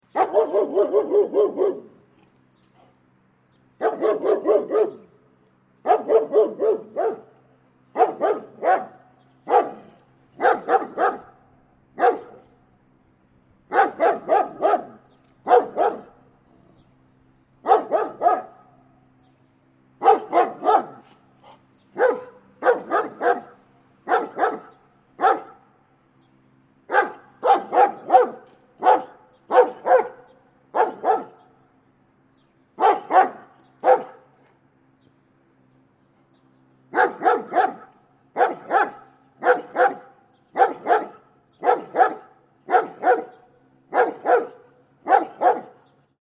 Old Dog Barking Sound
Animal Sounds / Dog Sounds / Sound Effects
Large-dog-barks.mp3